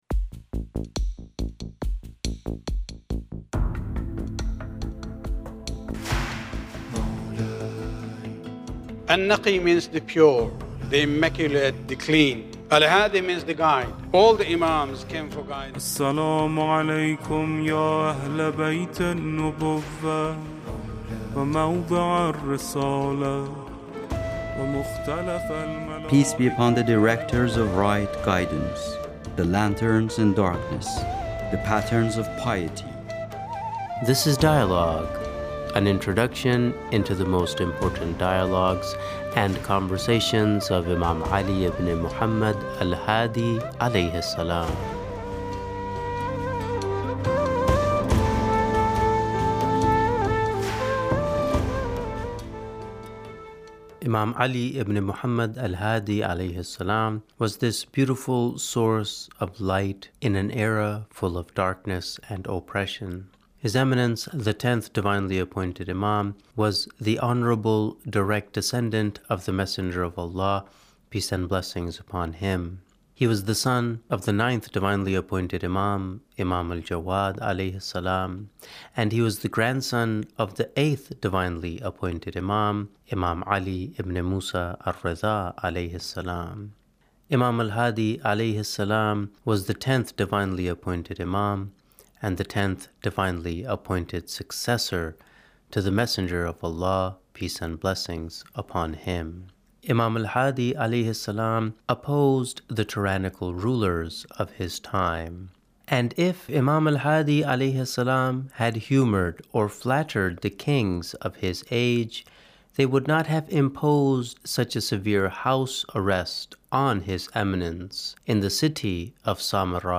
A look at some of the greatest dialogues of Imam al-Hadi with different people: two scholars talk about the knowledge of Imam Al-Hadi